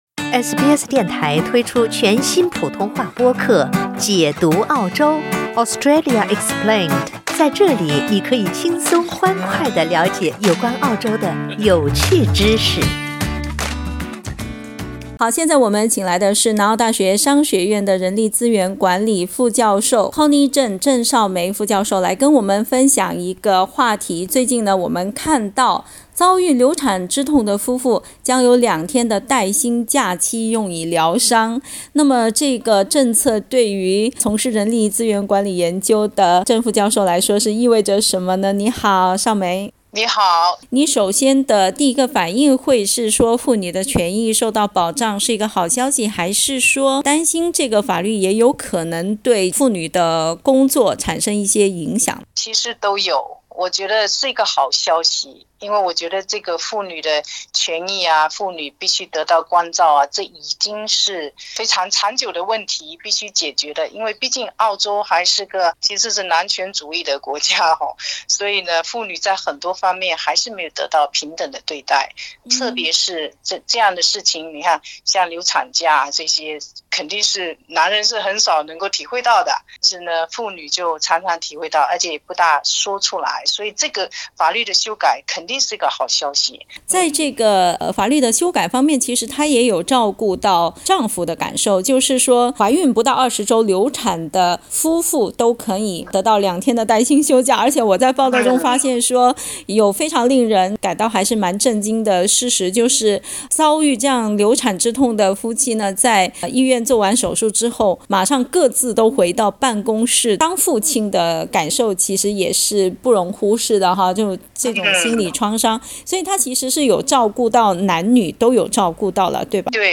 （點擊上圖收聽寀訪）